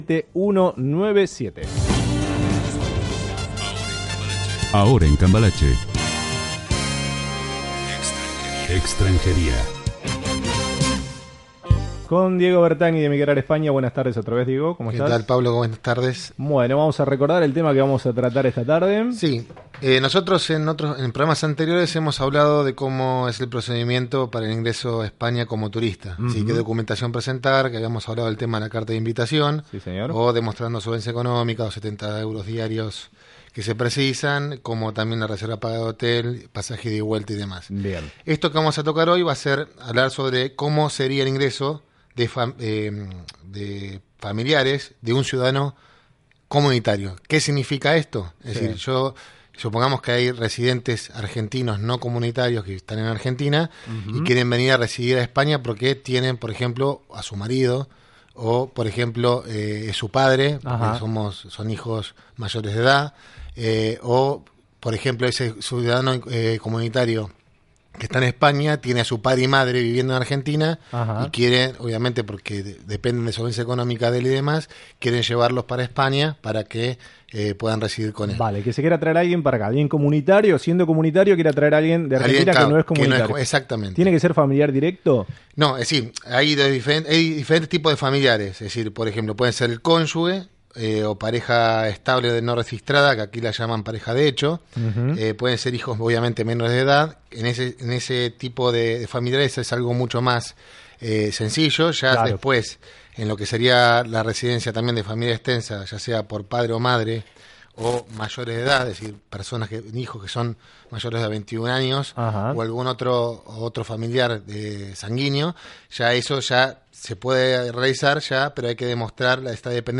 Como Ingresar como familiar de residente comunitario en España En Emigrar España les acercamos la nota en la radio Cambalache sobre tips para la entrada de familiares de ciudadanos comunitarios residentes.